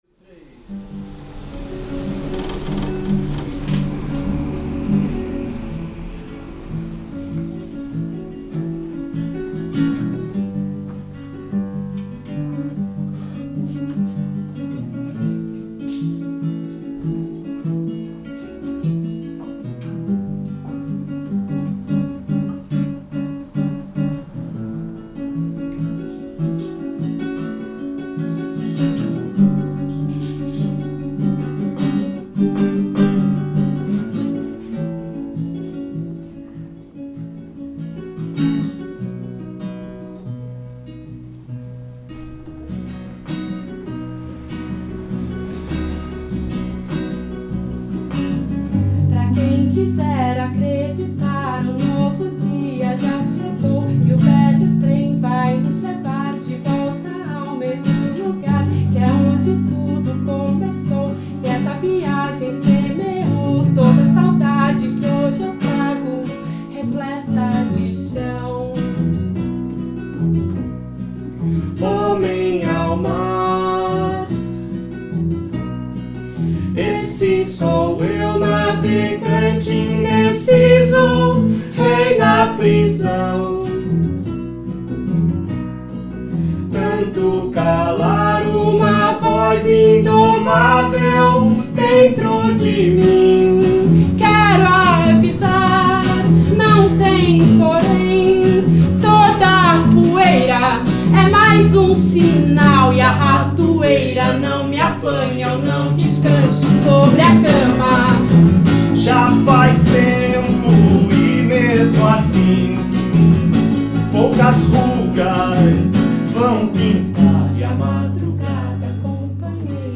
Apresentação na Jambrohouse.